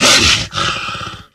pdog_attack_1.ogg